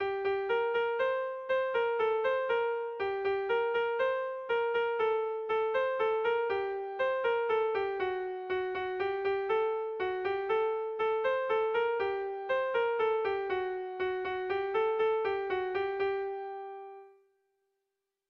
Bertso melodies - View details   To know more about this section
Erlijiozkoa
ABB